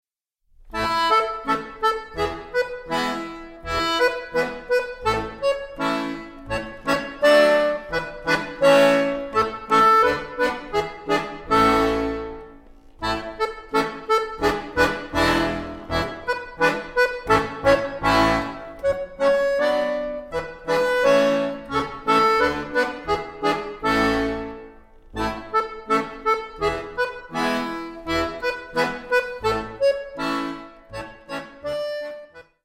Liederbuch + Mitsing-CD